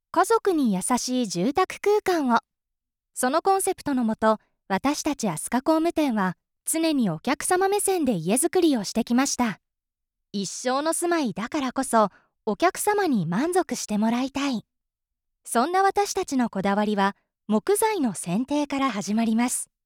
元CATVアナウンサーが温かみのある爽やかな声をお届けします。
やさしい